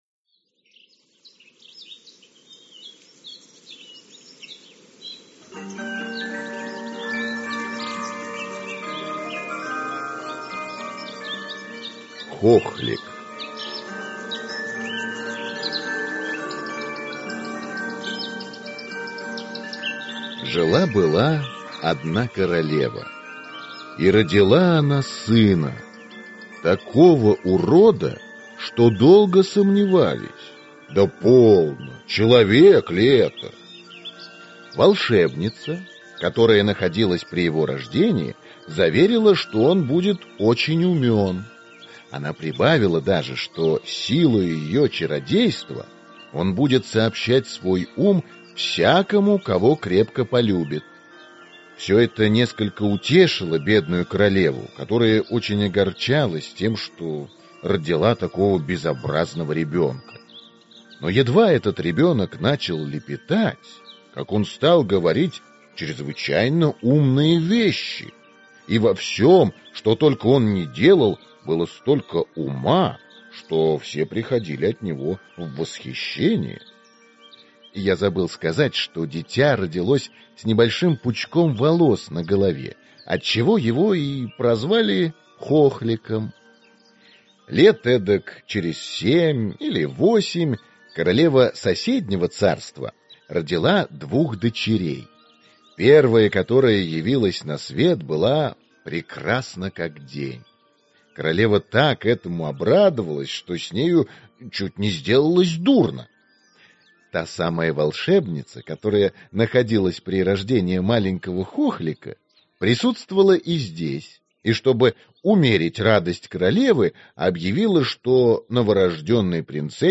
Аудиокнига Хохлик | Библиотека аудиокниг